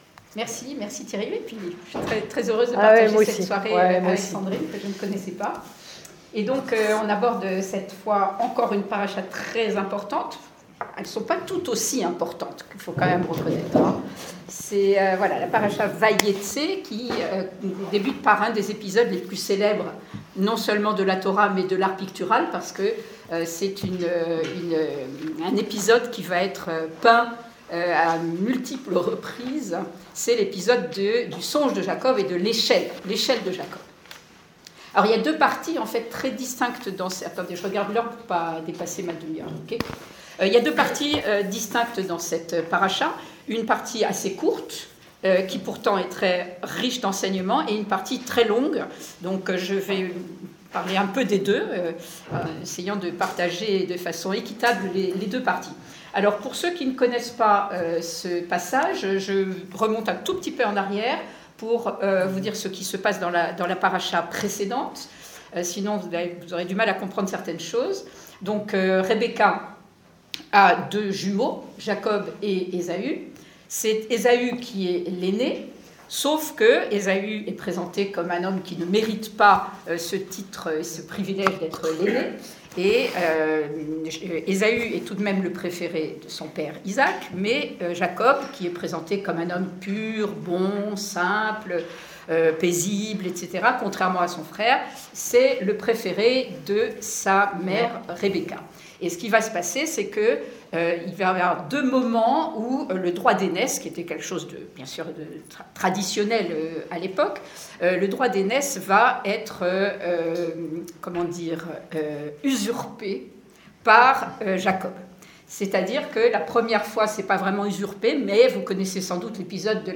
Dans le cadre d'un cycle de soirées entre juifs et chrétiens, une voix juive et une voix chrétienne proposent une étude sur une section biblique qui correspond à la lecture de l'office communautaire du shabbat matin.
Étude biblique